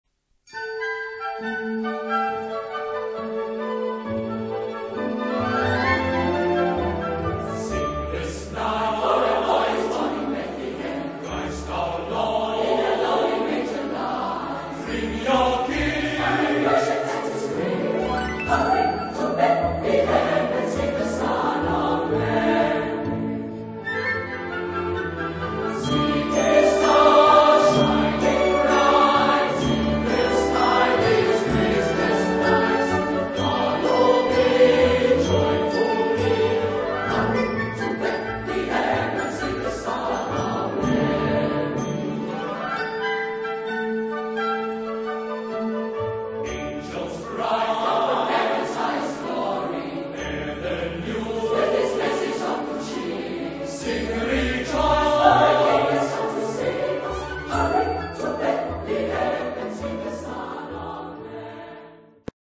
Epoque: 20th century
Genre-Style-Form: Choir ; Christmas song
Type of Choir: SATB  (4 mixed voices )
Instruments: Piano (1)